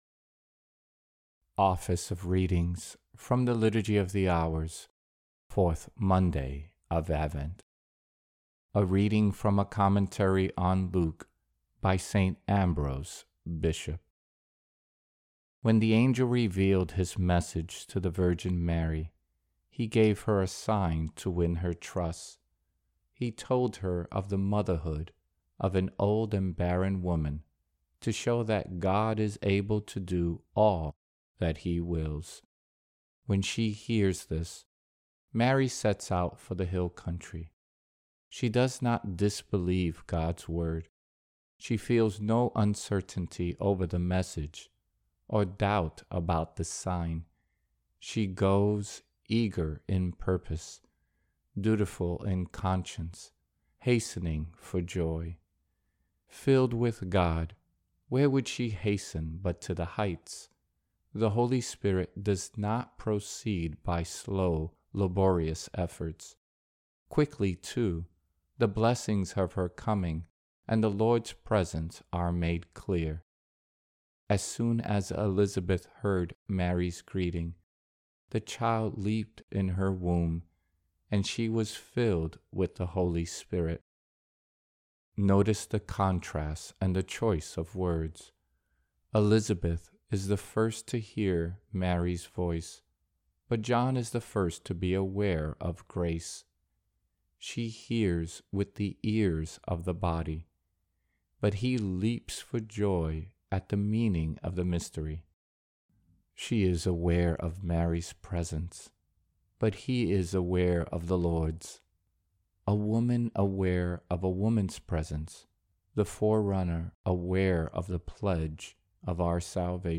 Office of Readings – 4th Monday of Advent